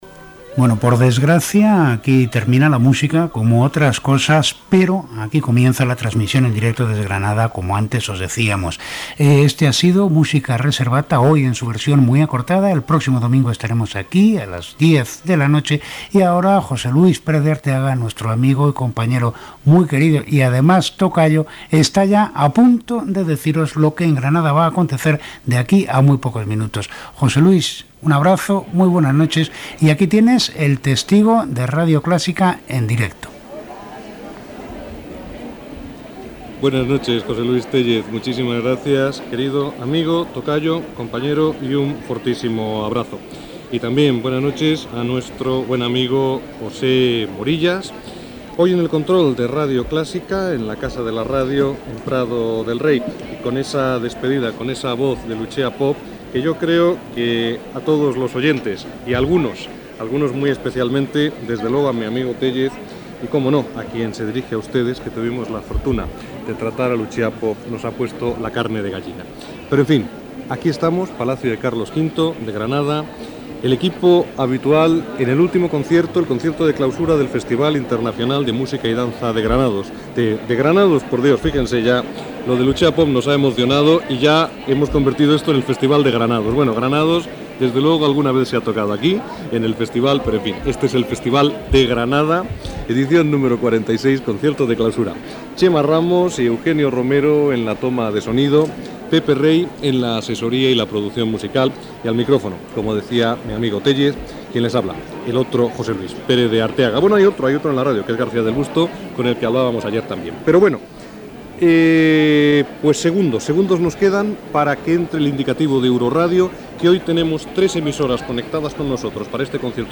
Comiat del programa, inici de la transmissió, des del Palacio de Carlos V, del concert de clausura del 46 Festival Internacional de Música y Danza de Granada, indicatiu d'Euroradio, emissores connectades, obres que s'escoltaran, dades de l'Orquestra Simfònica Portuguesa i presentació de la primera peça musical